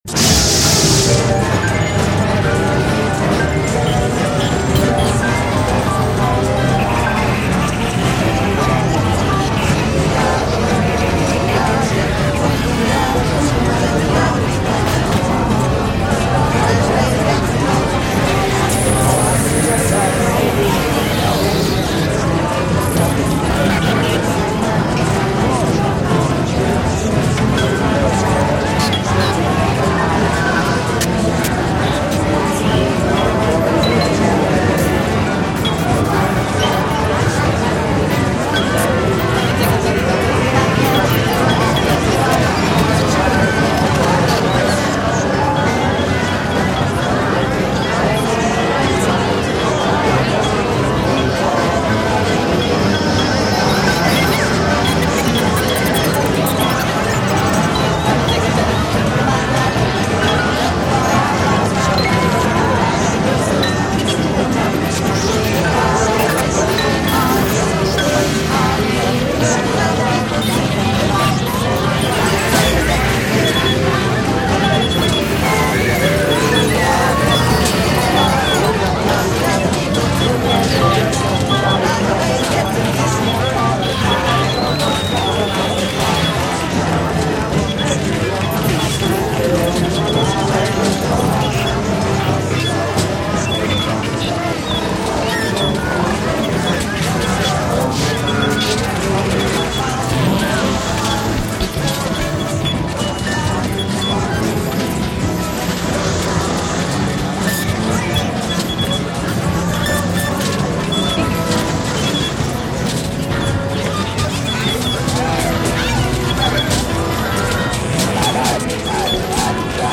mixagem do disco “we’re only in it for the money” de frank zappa, com todas as faixas iniciando ao mesmo tempo. comprimi esse álbum em 1999, na energia do bug do milênio.